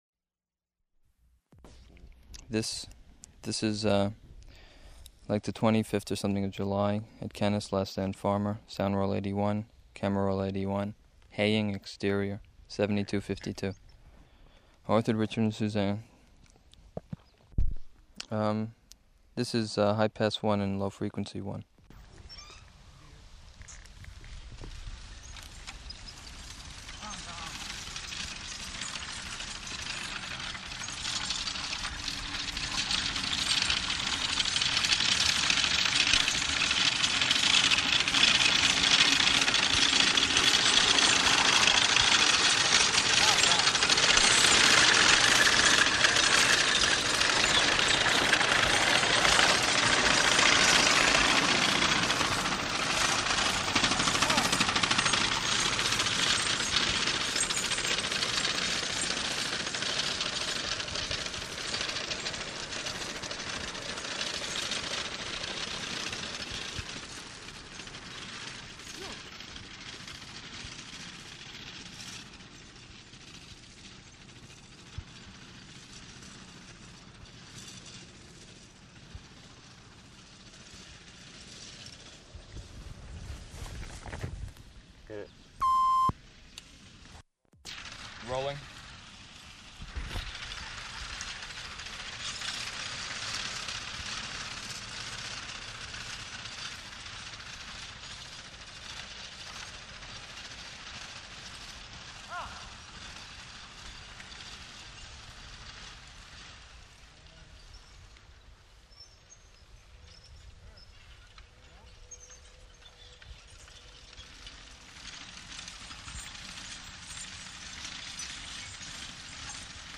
Format 1 sound tape reel (Scotch 3M 208 polyester) : analog ; 7 1/2 ips, full track, mono.